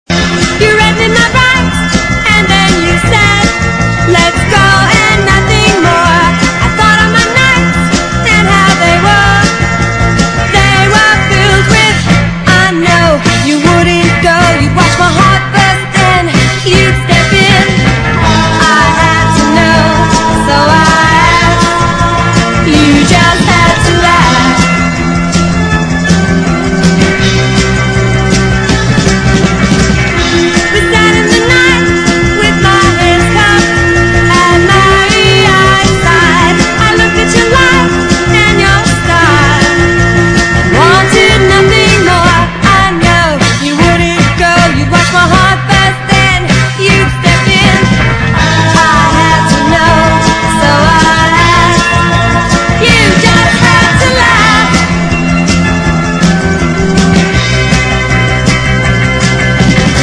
子供コーラス入りのサイケデリック・ポップ/ソフトロック・ナンバーを収録！